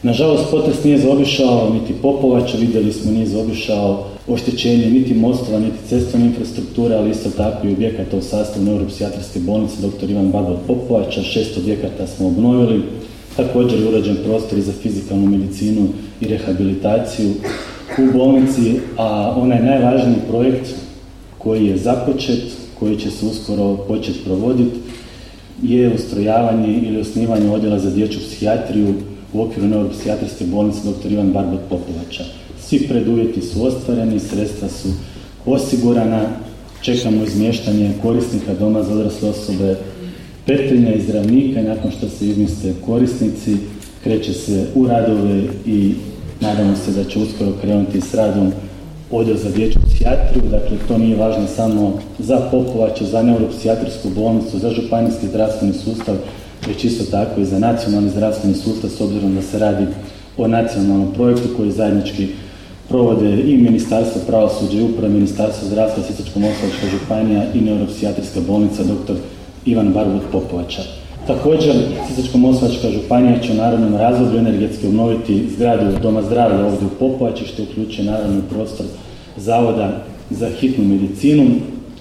Svečanom sjednicom Gradskog vijeća, Popovača je u srijedu, 21. lipnja 2023. godine proslavila Dan grada i blagdan svoga zaštitnika, sv. Alojzija Gonzage.
Svim stanovnicama i stanovnicima Dan grada Popovače čestitao je župan Ivan Celjak, u svojstvu izaslanika predsjednika Vlade RH Andreja Plenkovića i predsjednika Hrvatskog sabora Gordana Jandrokovića, istaknuvši tom prilikom izvrsnu suradnju Sisačko-moslavačke županije i Grada Popovače.